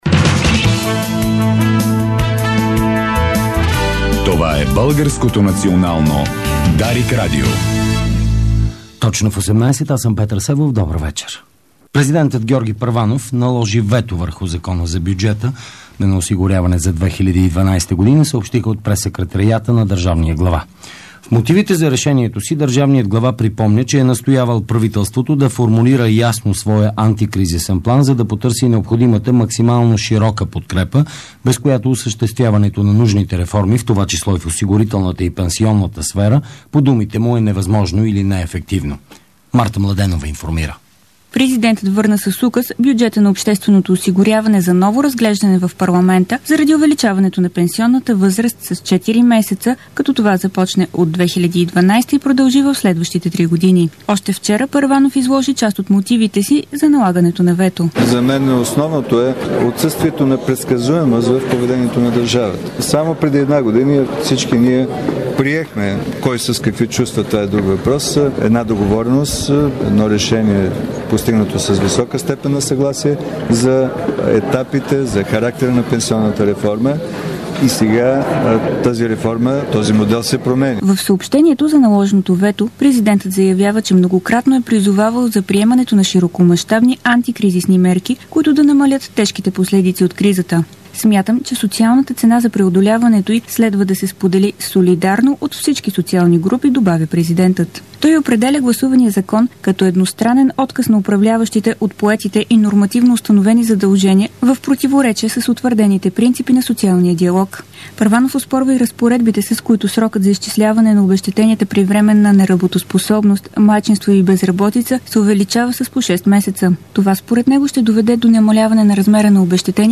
Обзорна информационна емисия - 11.12.2011